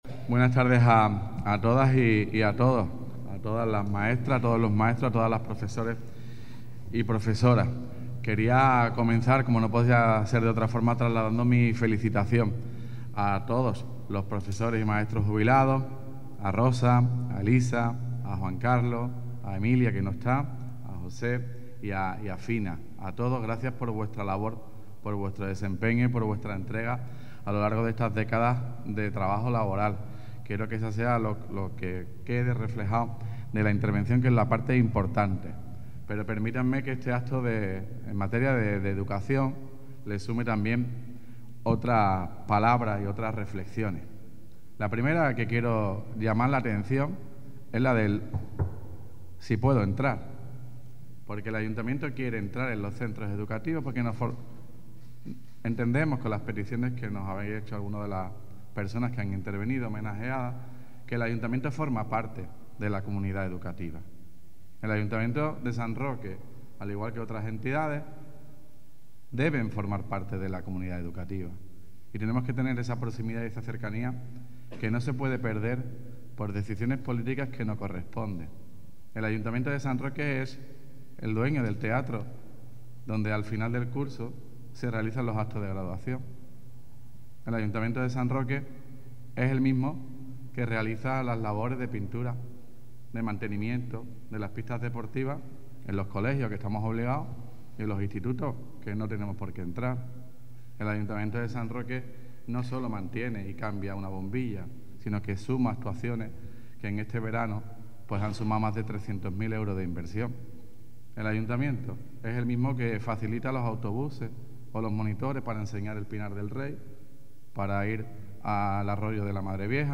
Para finalizar el acto, el alcalde, Juan Carlos Ruiz Boix, trasladó su felicitación a todos los profesores jubilados y agradeció su labor entrega durante su época laboral.
ACTO_PROFESORES_JUBILADOS_TOTAL_ALCALDE.mp3